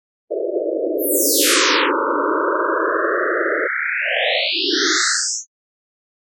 Coagula is a bitmap to sound converter.